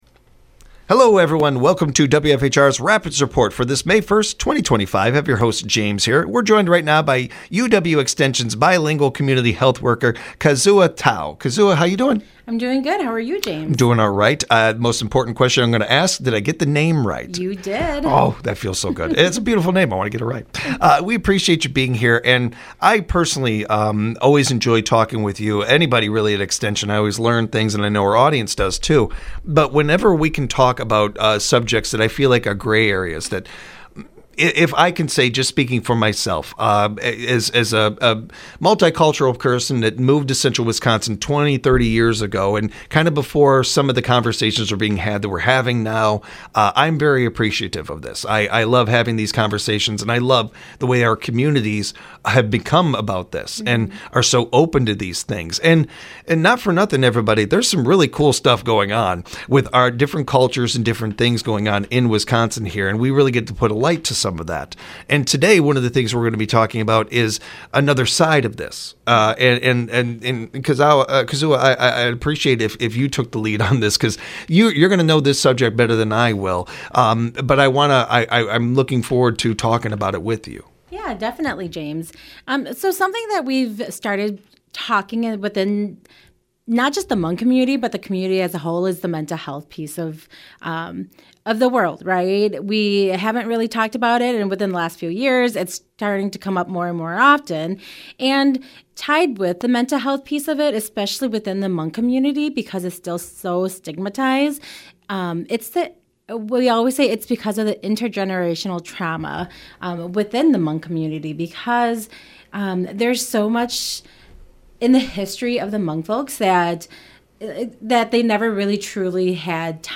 A Conversation about Intergenerational Trauma May 1